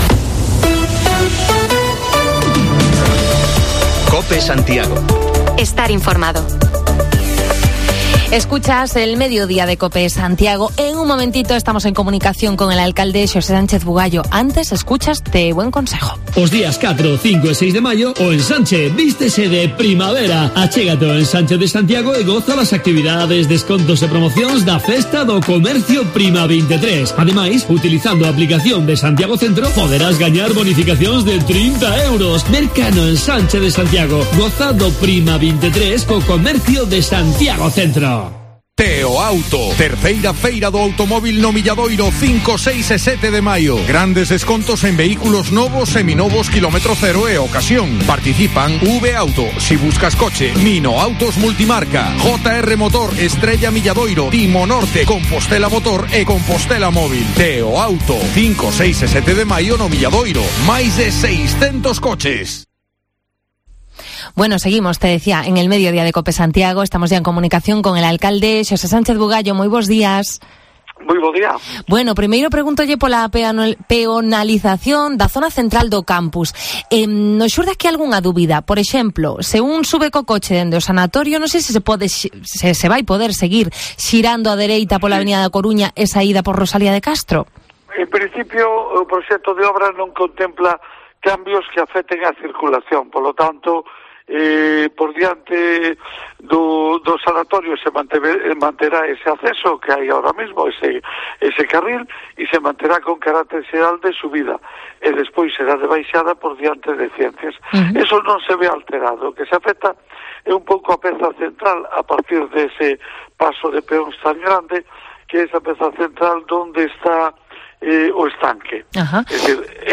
Entrevista al Alcalde Sánchez Bugallo sobre varios temas de actualidad y que nos llegan vía whatsapp: el vandalismo que quemó un columpio del Parque de Ramírez y que nos costará más de 1.000 euros de las arcas municipales o los contenedores de Salgueiriños, que abandonarán por fin esa explanada